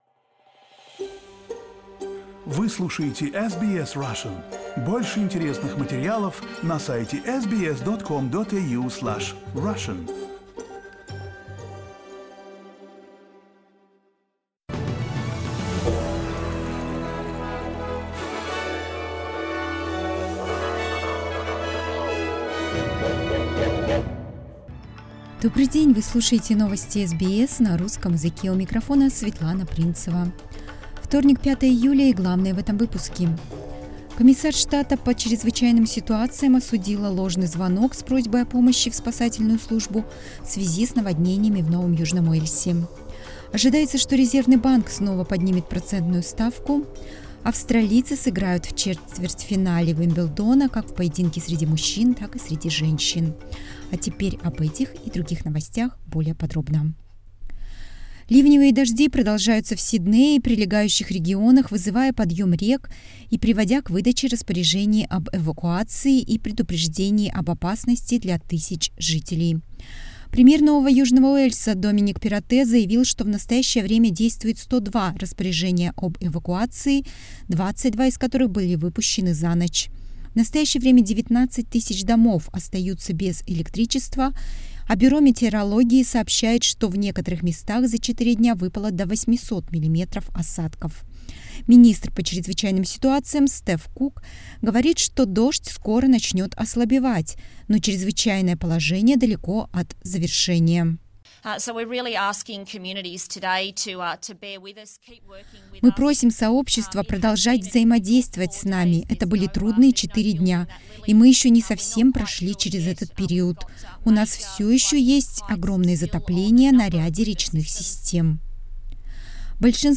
Listen to the latest news headlines in Australia from SBS Russian